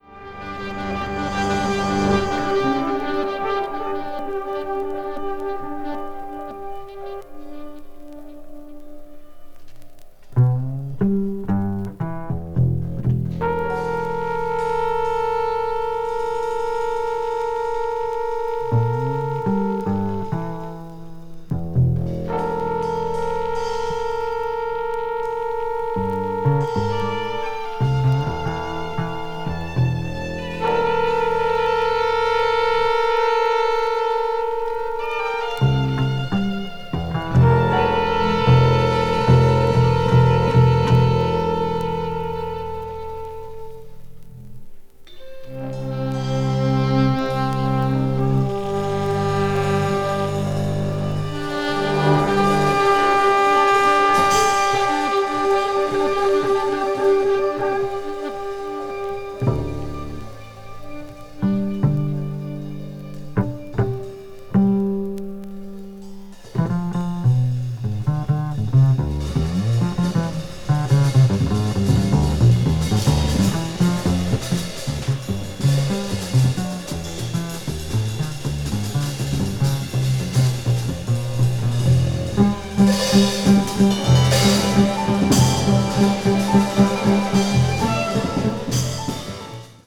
media : EX-/EX-(薄い擦れによるわずかなチリノイズが入る箇所あり)
リコーダーやフルート、ザイロフォンがエスニックな情緒を醸し出しながらミステリアスに風景描写する長編曲を収録。
avant-jazz   ethnic jazz   free improvisation   free jazz